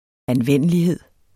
Udtale [ anˈvεnˀəliˌheðˀ ]